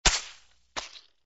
AA_drop_sandbag_miss.ogg.mp3